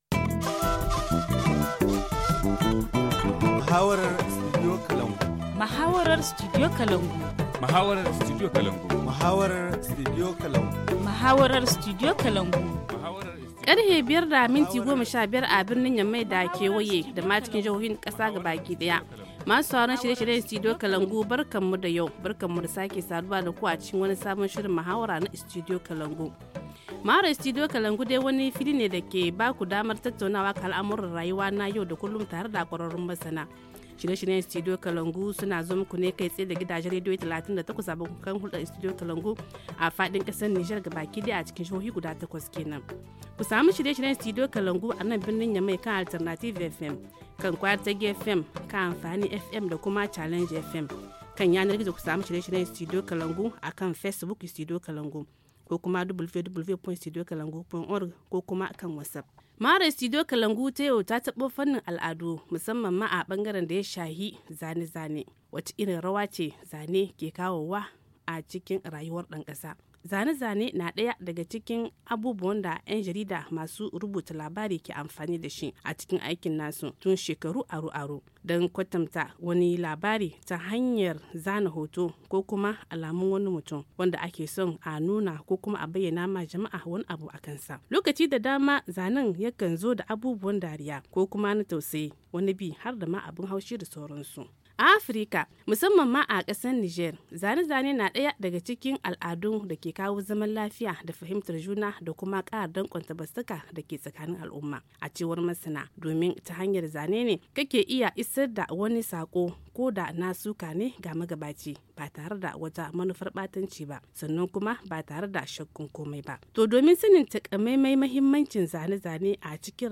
Le forum en haoussa du 04/05/2019-La caricature au Niger: quel impact sur le citoyen? - Studio Kalangou - Au rythme du Niger